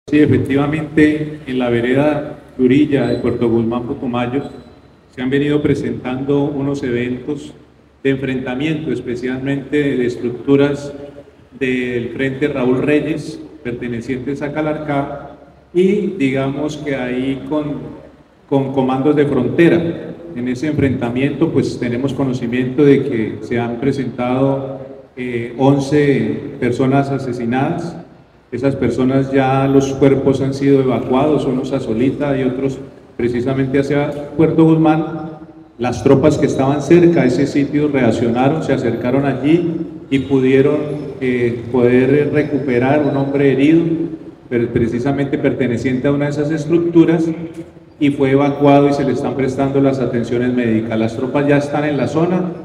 GENERAL_LUIS_CARDOZO_SANTAMARIA_COMBATES_-_copia.MP3